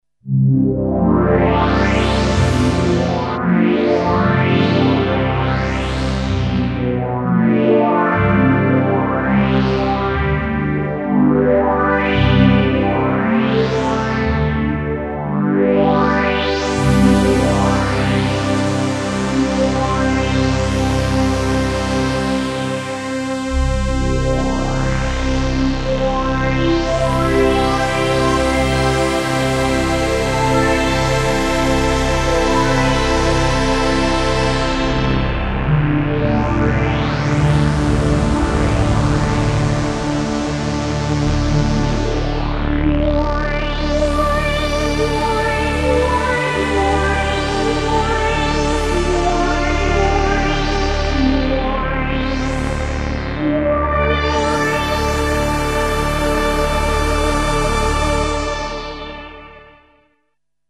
resonant pad II
demo pan game
demo another evolving VCF pad
demo noise LFO